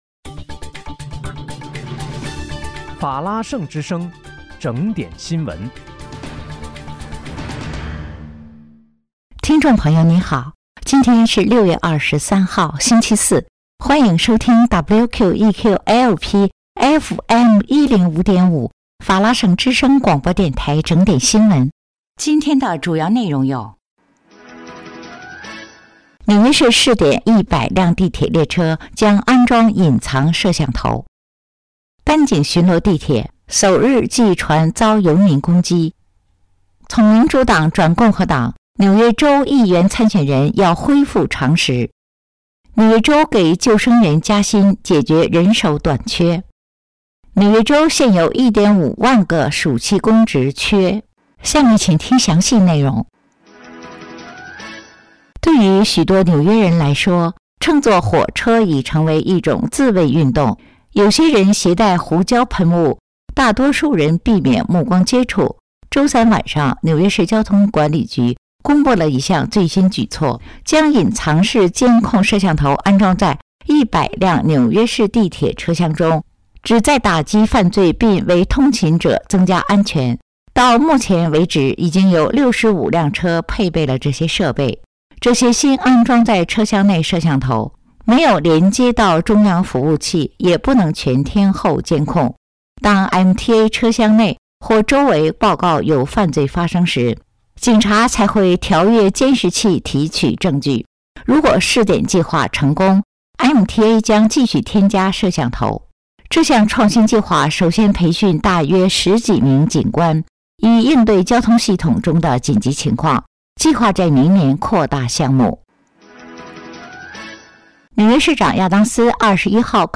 6月23日（星期四）纽约整点新闻